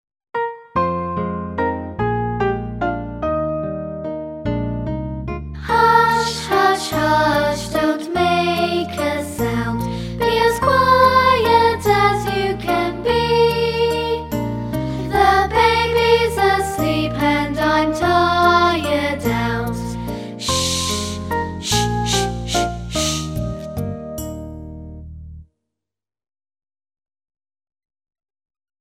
每个发音都配有一个用著名曲调填词的短歌和动作图示。